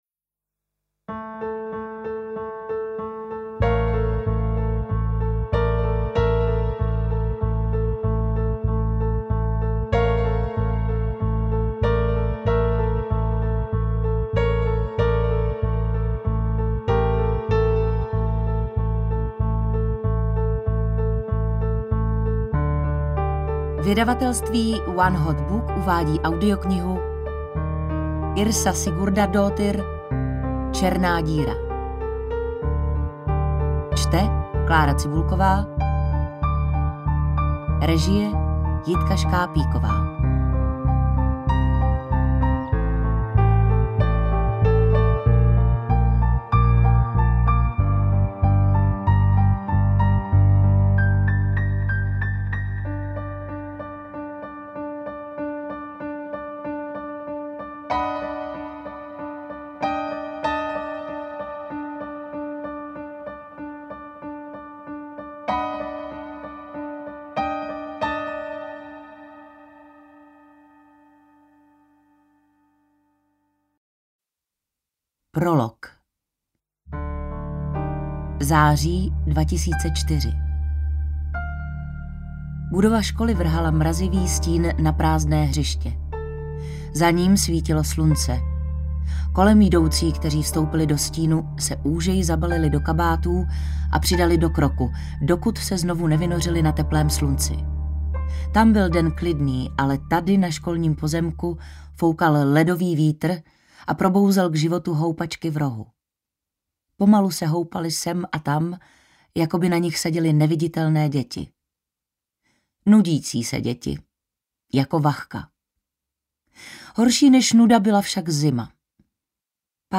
Interpret:  Klára Cibulková
AudioKniha ke stažení, 37 x mp3, délka 12 hod. 48 min., velikost 696,0 MB, česky